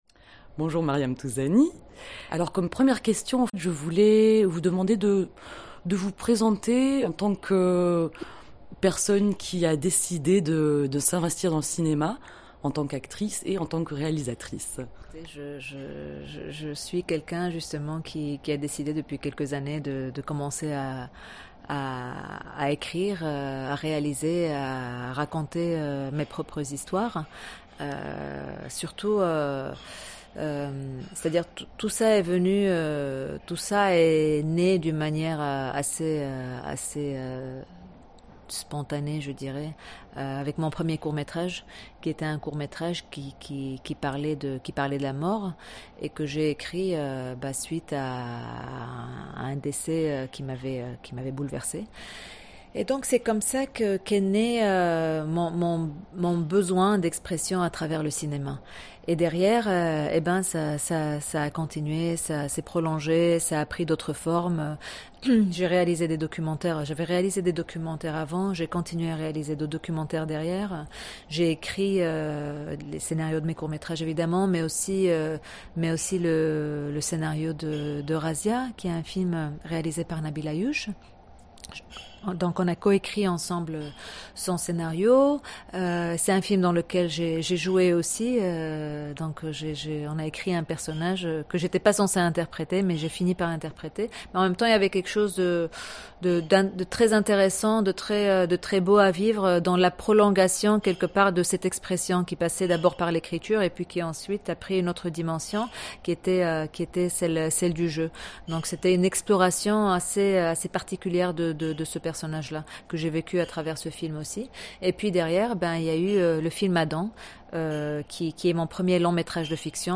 INTERVIEW DE MARYAM TOUZANI
itv_maryam_touzani.mp3